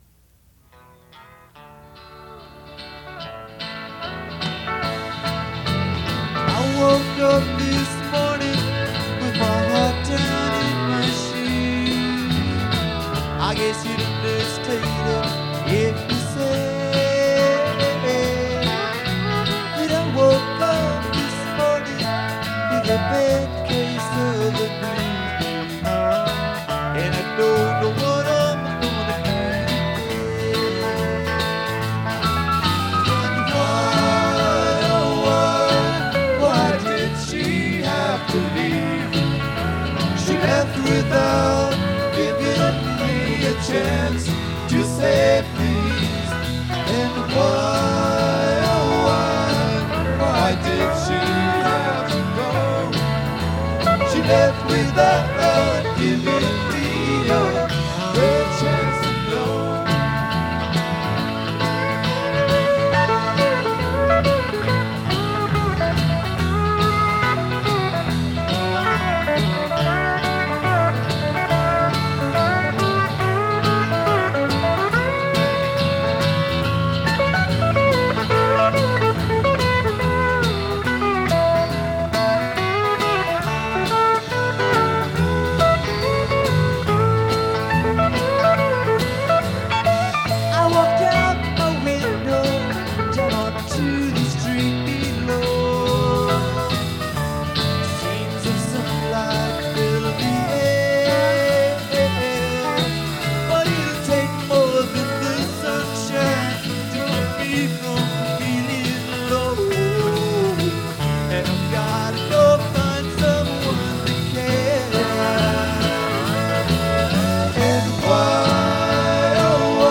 Fillmore East New York, NY April 28th, 1971
Rhythm Guitar
Pedal Steel Guitar